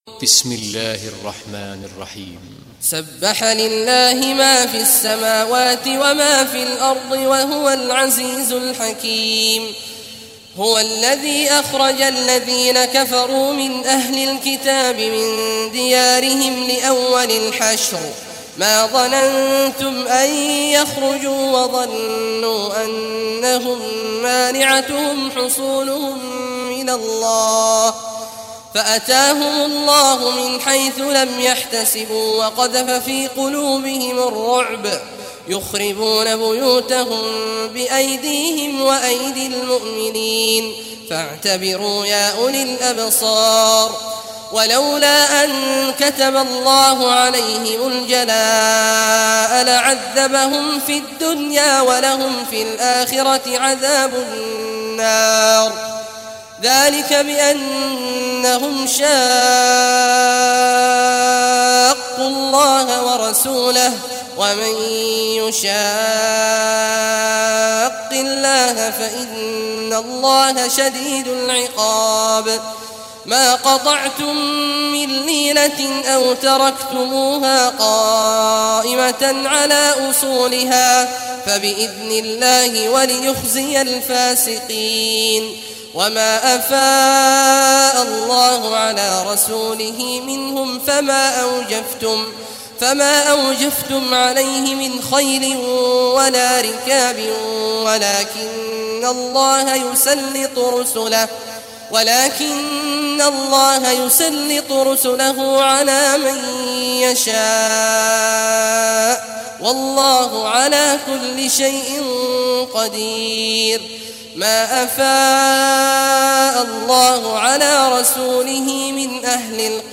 Surah Al-Hashr Recitation by Sheikh Awad al Juhany
Surah Al-Hashr, listen or play online mp3 tilawat / recitation in Arabic in the beautiful voice of Sheikh Abdullah Awad Al Juhany.